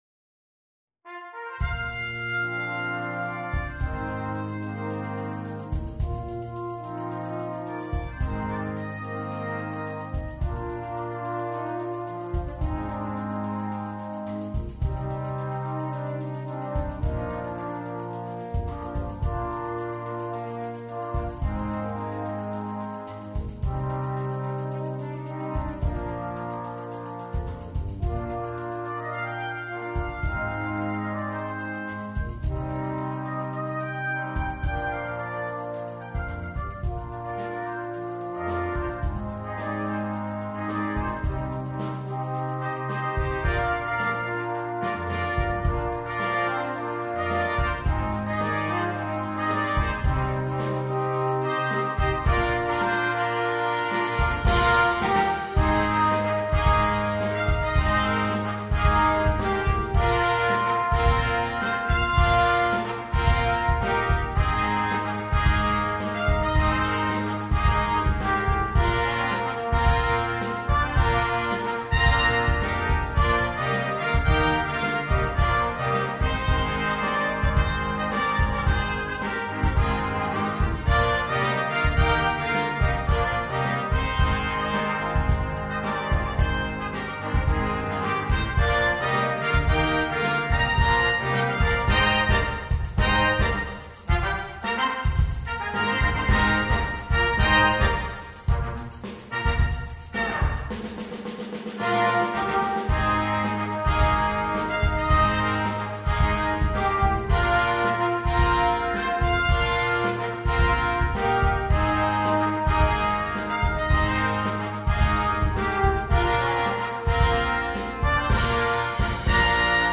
Besetzung: (3Tp, Hn, 2Tb, Tba, Dr)